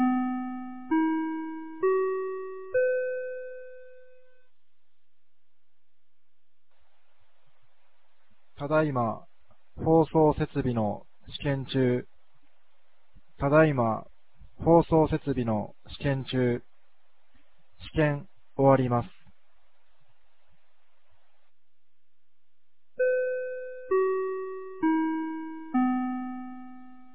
2025年09月20日 16時03分に、由良町から全地区へ放送がありました。
放送音声